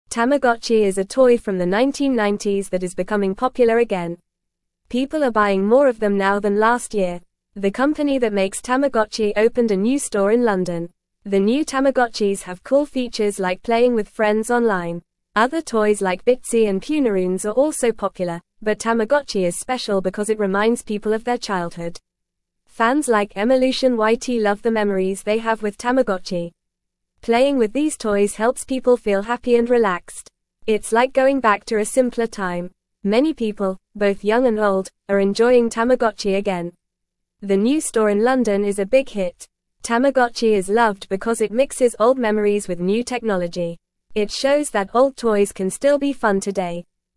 Fast
English-Newsroom-Lower-Intermediate-FAST-Reading-Tamagotchi-Toy-Makes-People-Happy-and-Relaxed.mp3